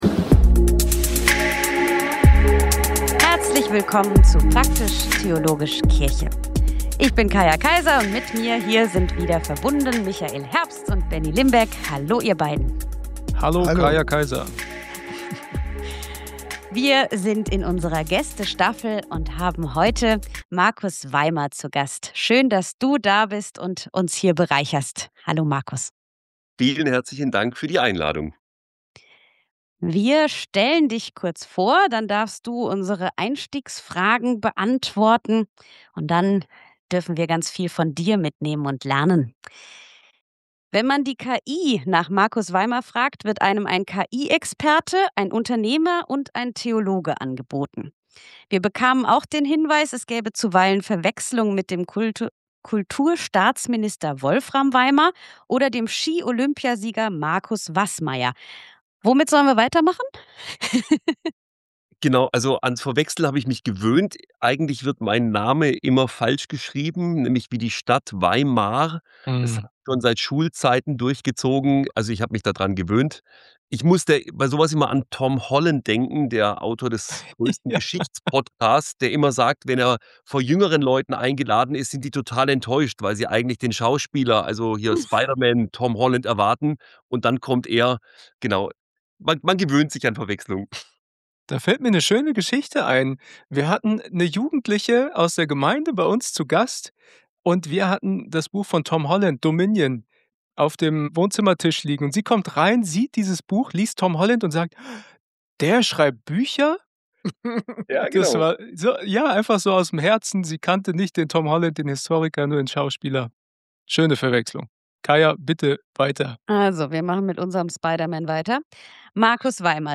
Wir befinden uns in unserer zweiten Staffel, in der wir mit faszinierenden Gästen ins Gespräch kommen! Wir reden mit ihnen über das, was sie begeistert und wo sie Chancen und neue Perspektiven für die Kirche sehen.